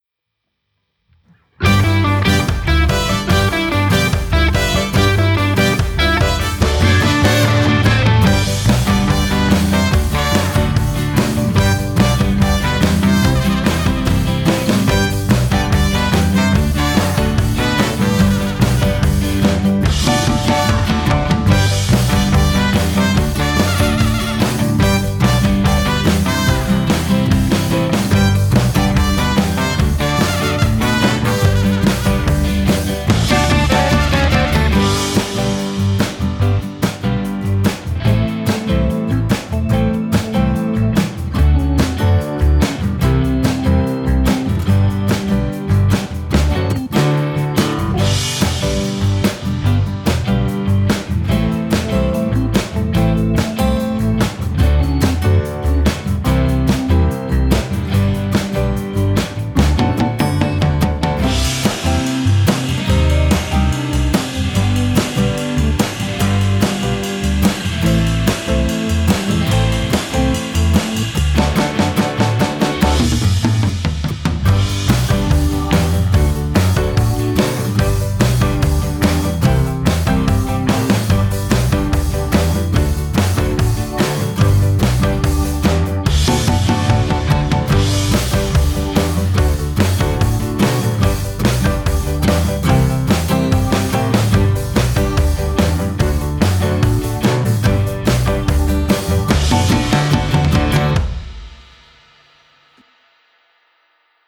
Рок-н-ролльная зарисовка.
Бодрая рок-н-ролльная импровизация примерно в таком стиле, с кучей гитар, фортепиано и трубами.
Пытался сделать так, чтобы воспринималось всё органично, пока без особого сведения, довольно сырой материал.